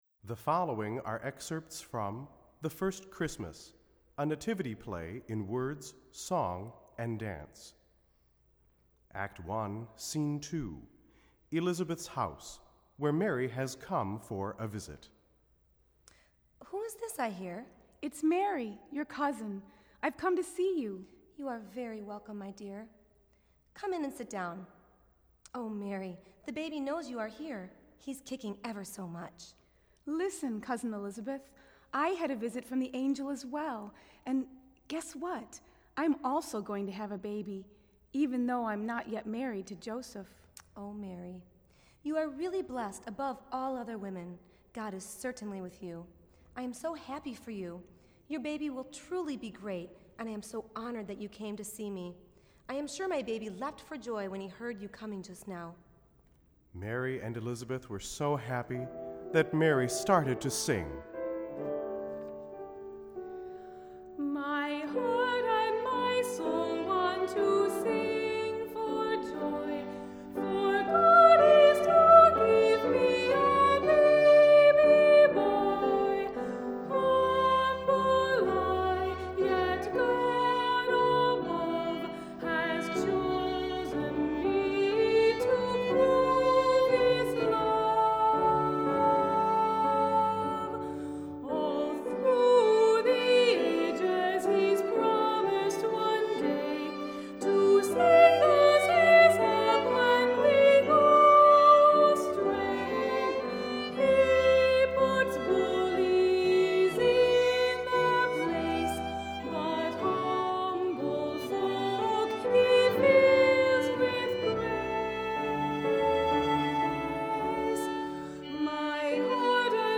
Voicing: Unison; Two-part equal